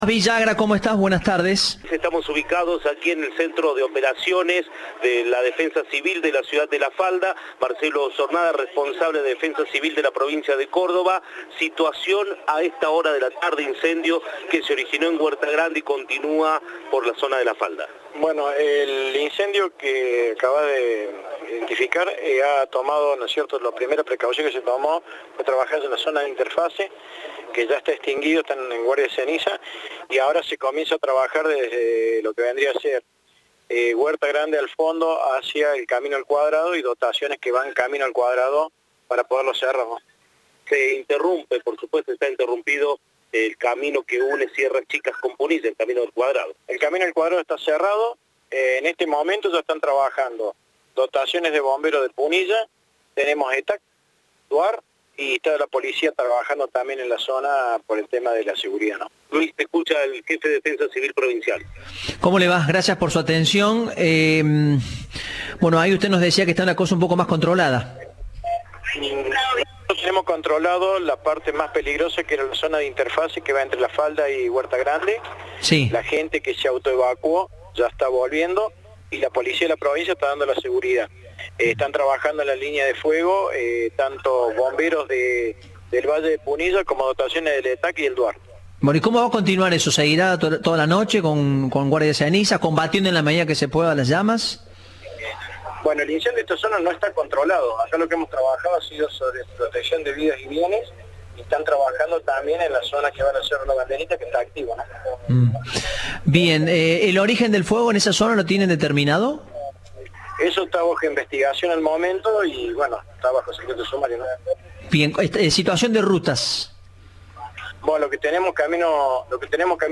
En diálogo con Cadena 3, el secretario de Gestión de Riesgo Climático y Catástrofes, Claudio Vignetta precisó que comenzó en Huerta Grande y luego pasó a La Falda.